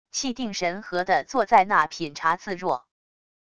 气定神和的坐在那品茶自若wav音频生成系统WAV Audio Player